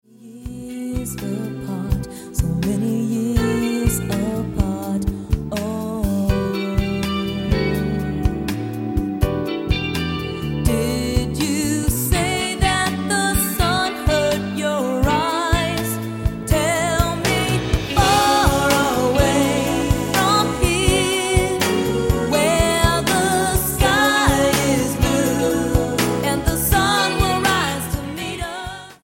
soft jazz project
Style: Pop